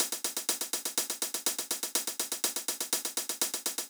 ENE Beat - Hats.wav